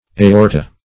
Aorta \A*or"ta\, n. [NL., fr. Gr.